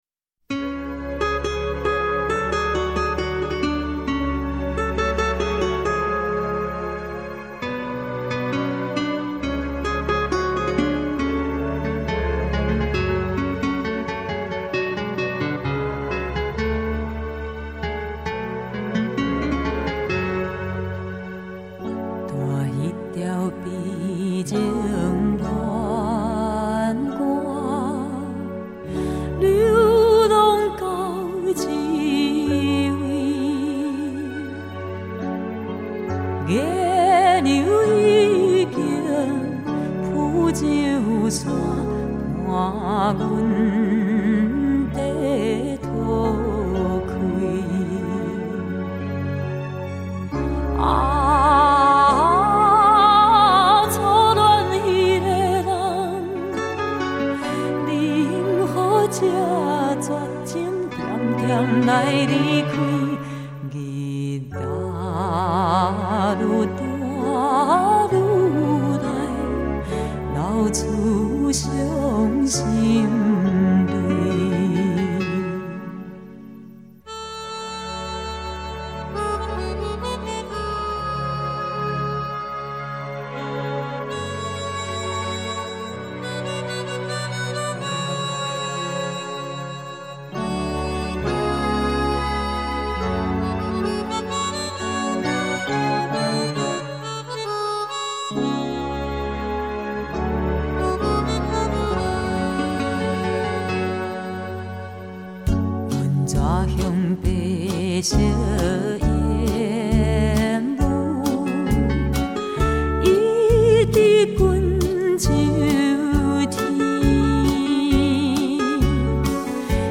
台语老歌